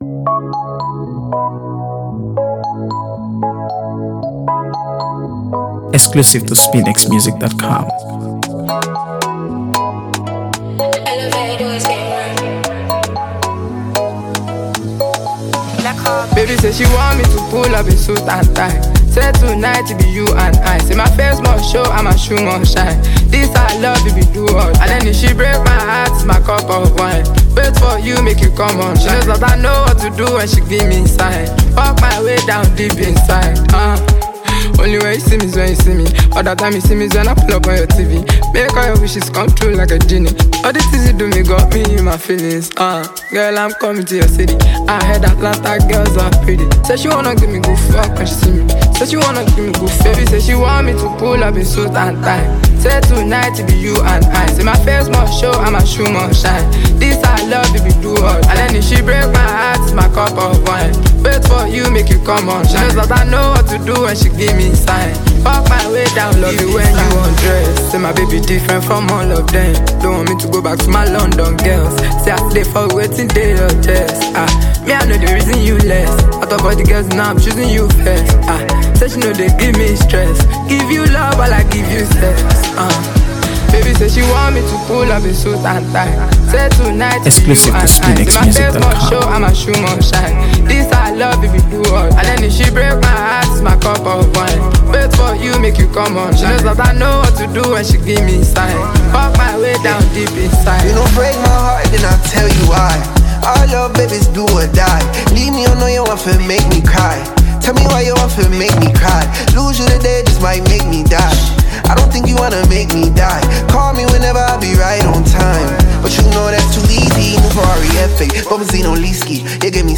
AfroBeats | AfroBeats songs
Smooth, stylish, and emotionally rich
soulful delivery
laid-back yet expressive flow
Fusing Afrobeats, R&B, and trap elements